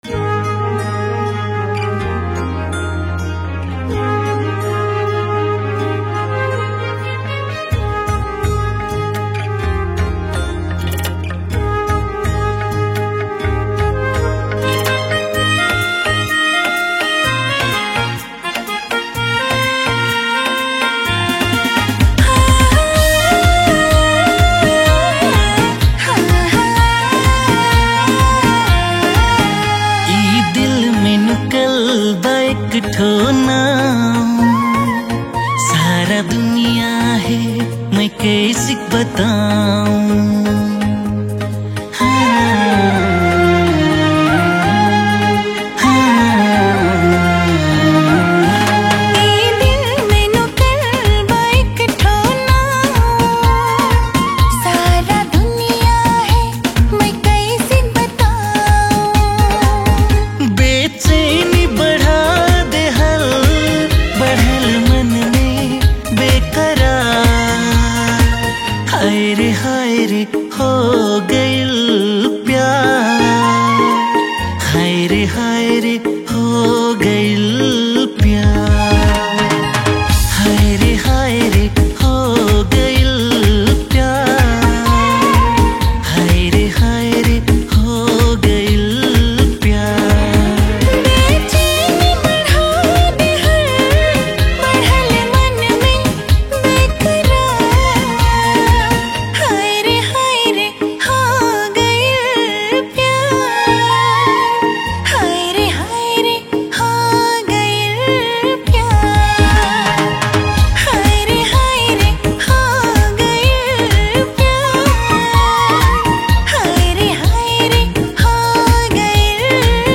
Tharu Romantic Song Tharu Item Dancing Song